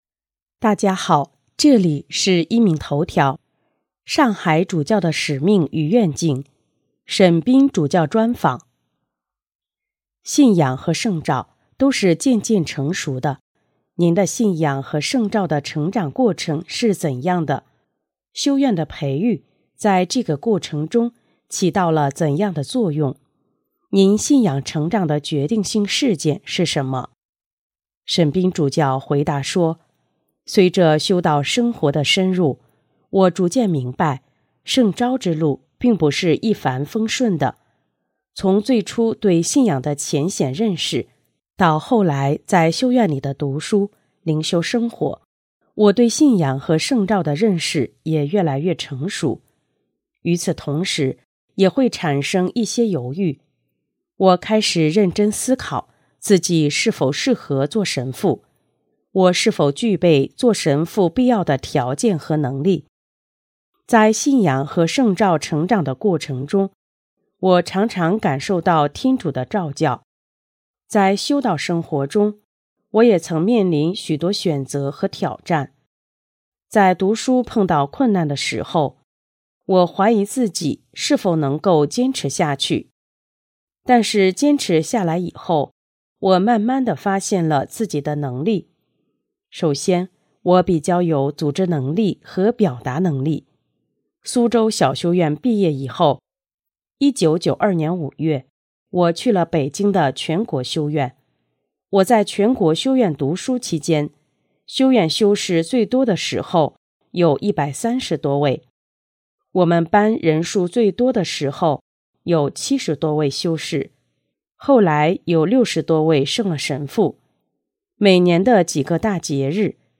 【壹明头条】| 上海沈斌主教专访（二）:关于你的信仰培育和圣召及晋铎后的生活